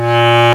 Index of /m8-backup/M8/Samples/Fairlight CMI/IIX/REEDS
BASSCLAR.WAV